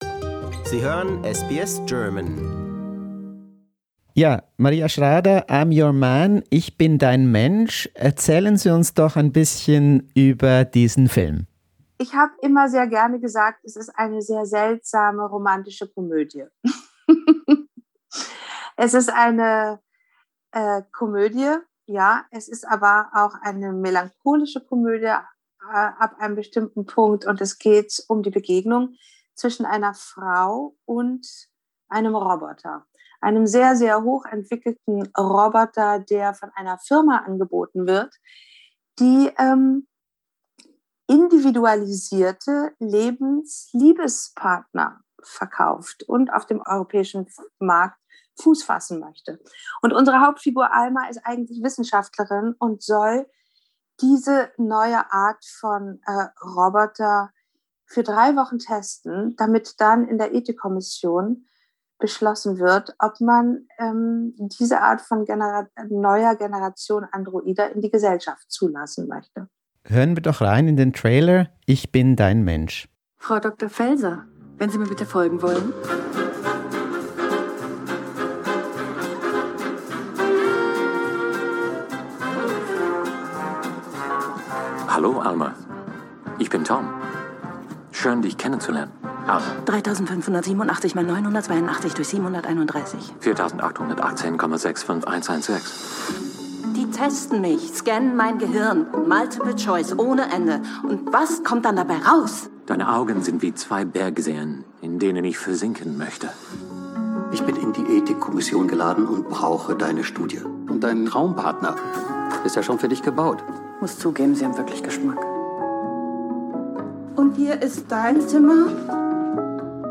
More on this in an SBS interview.